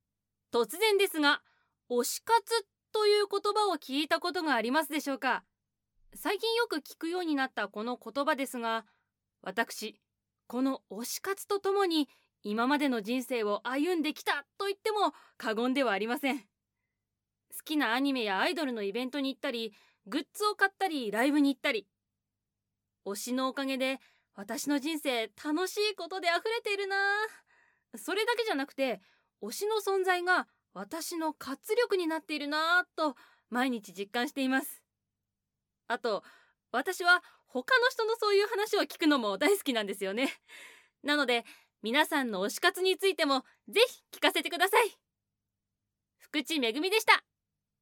方　言　：　東京都
フリートーク